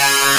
Hits